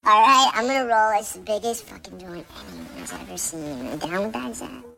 Make it really high or something like that, I think that would be funny.
::: helium voice
AD_BiggestFckingJoint_helium.mp3